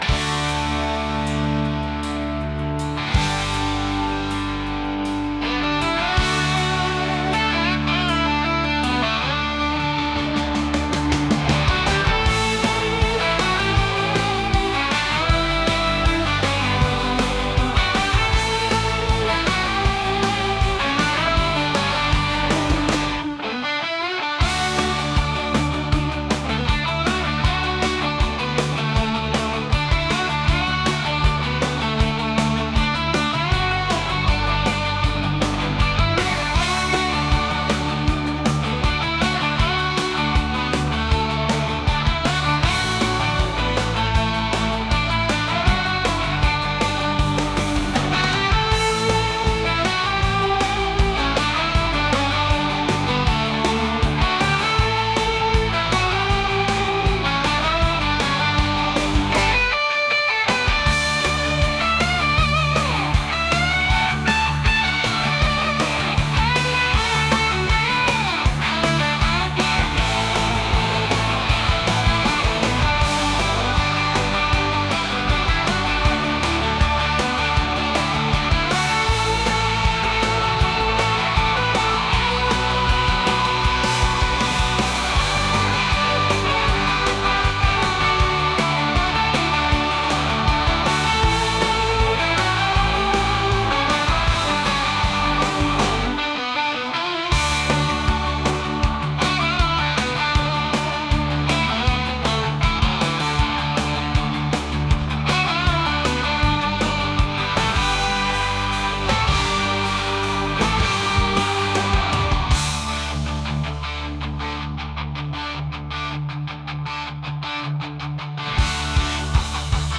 rock | cinematic | soulful